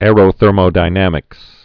(ârō-thûrmō-dī-nămĭks)